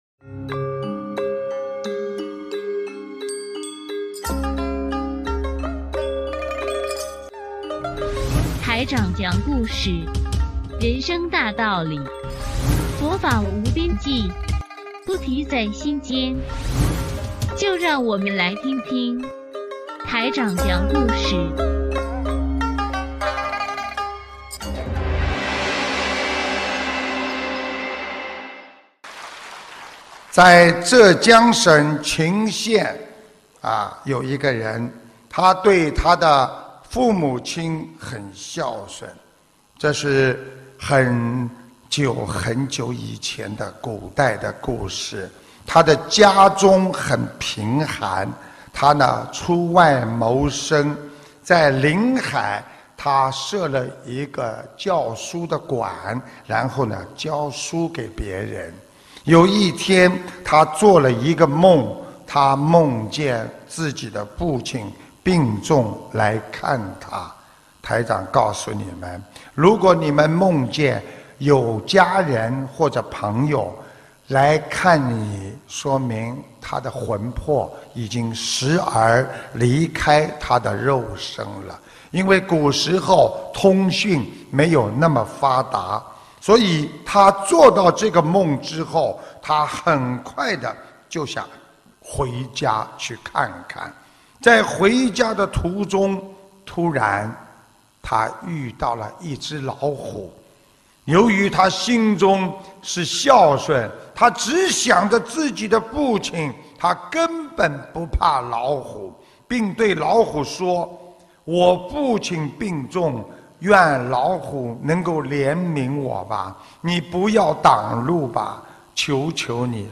音频：孝感退虎·师父讲小故事大道理